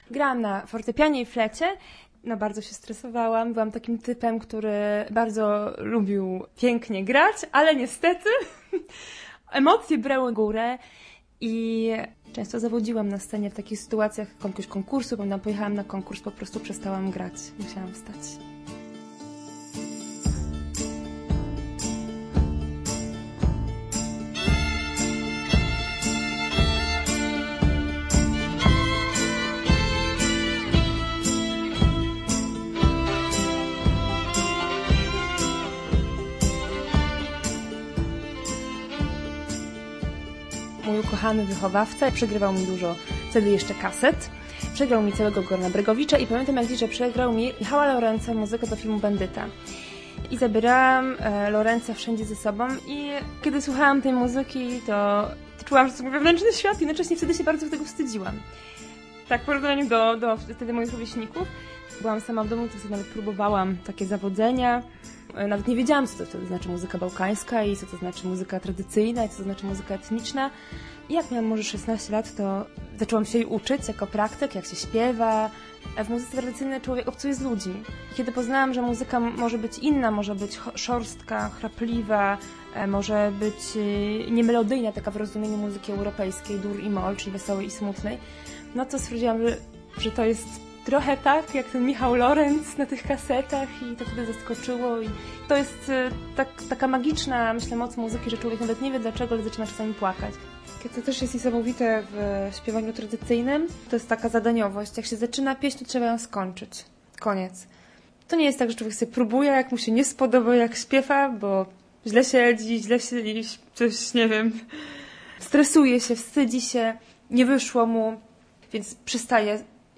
Po wakacjach wracamy z cyklem reportażowym.